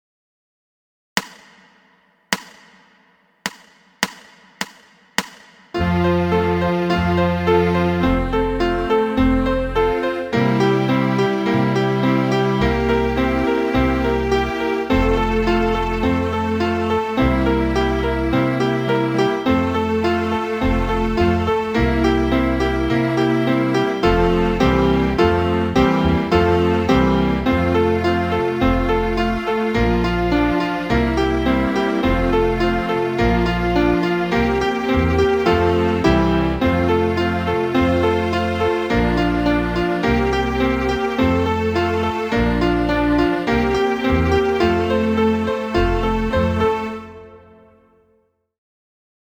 Trumpet Cover